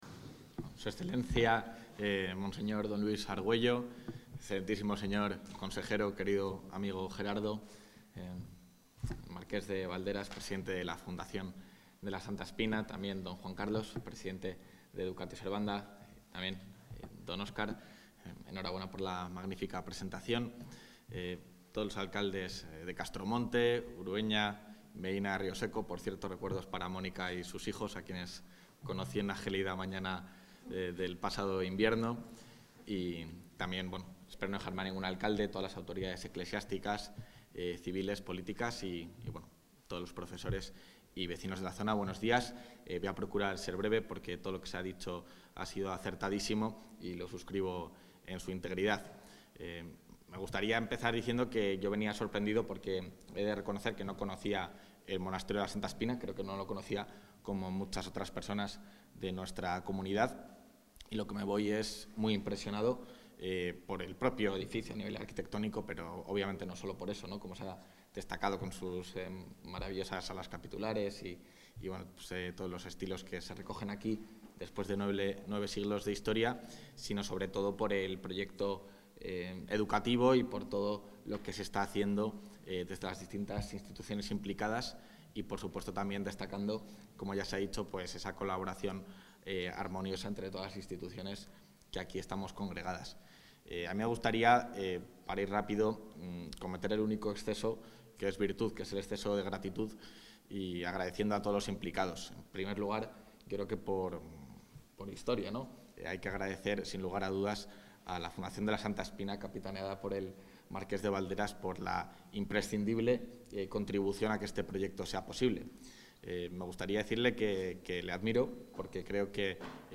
Intervención del vicepresidente.
El vicepresidente de la Junta de Castilla y León, Juan García-Gallardo, ha participado este sábado en la presentación oficial de la Fundación Educatio Servanda en la provincia de Valladolid.